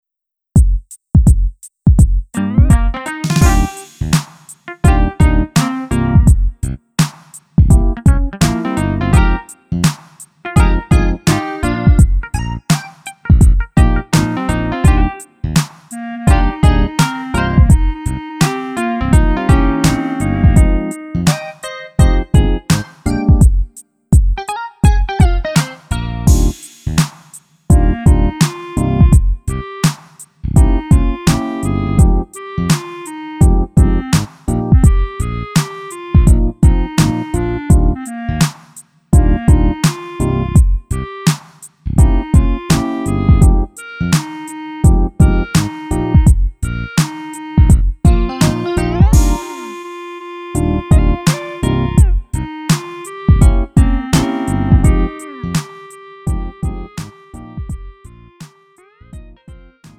음정 -1키 3:55
장르 구분 Lite MR